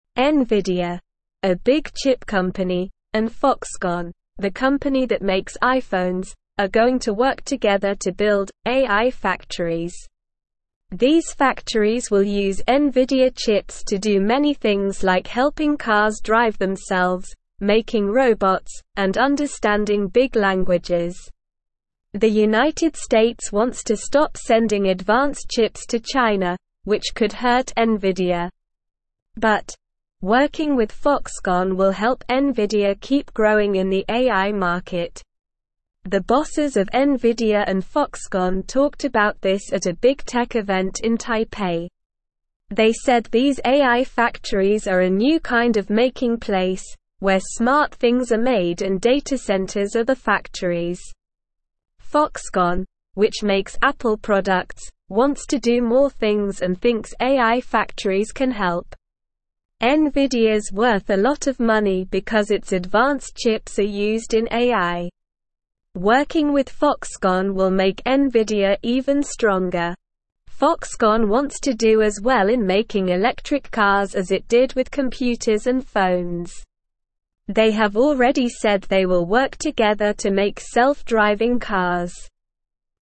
Slow
English-Newsroom-Beginner-SLOW-Reading-Nvidia-and-Foxconn-Join-Forces-to-Build-AI-Factories.mp3